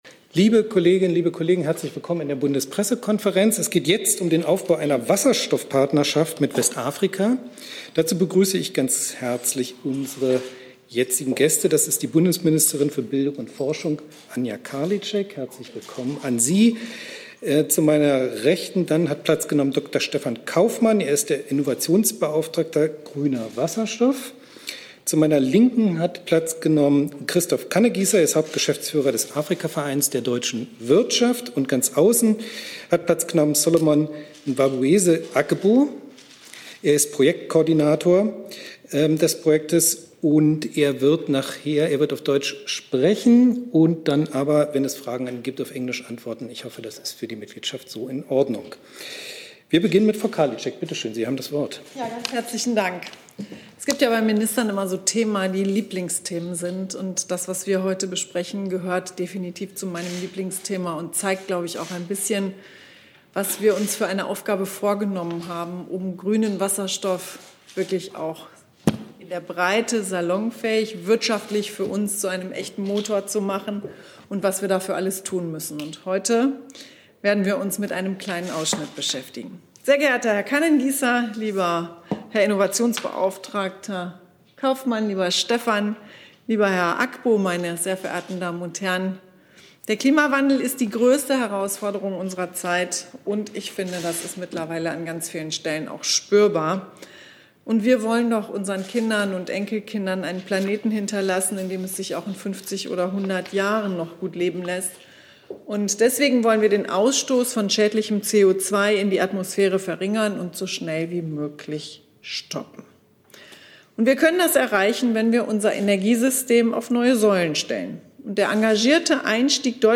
BPK - Forschungsministerin Anja Karliczek zur Wasserstoffpartnerschaft mit Westafrika - 20.05.2021 ~ Neues aus der Bundespressekonferenz Podcast